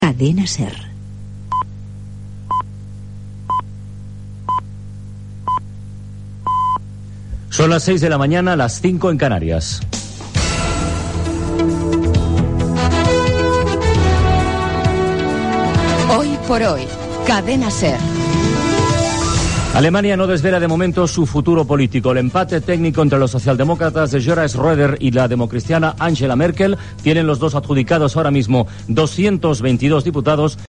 Primera edició del programa presentada per Carlos Francino.
Indicatiu de la cadena, horàries, indicatiu del programa, titular de les eleccions alemanyes.
Sintonia del programa, hora, eleccions alemanyes, el temps
Info-entreteniment